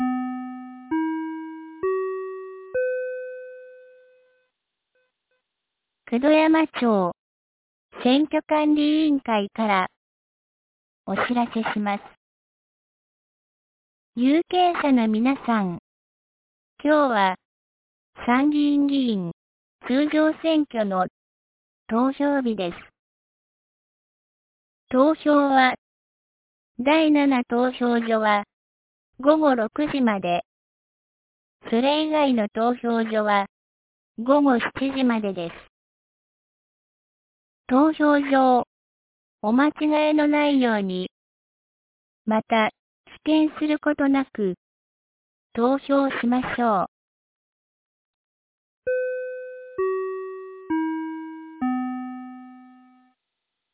2025年07月20日 10時00分に、九度山町より全地区へ放送がありました。
放送音声